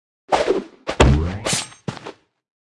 Media:Sfx_Anim_Ultra_Nita.wav 动作音效 anim 在广场点击初级、经典、高手和顶尖形态或者查看其技能时触发动作的音效
Sfx_Anim_Ultra_Nita.wav